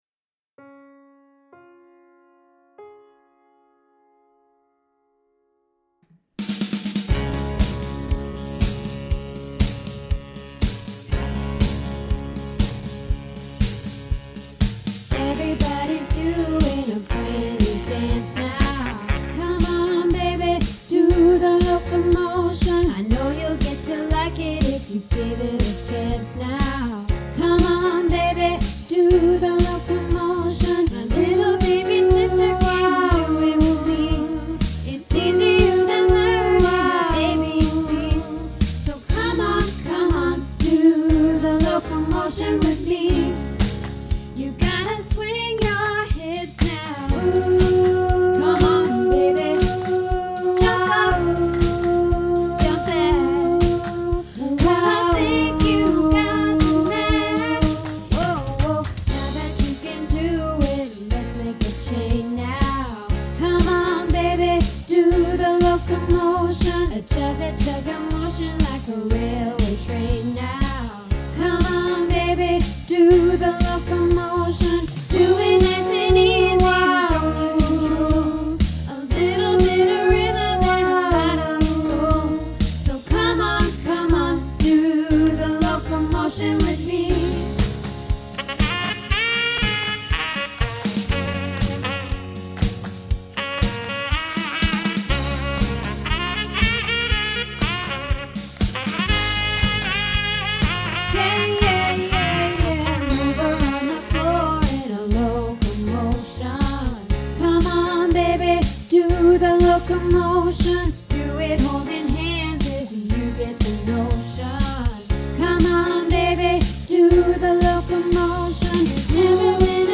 Locomotion - Tenor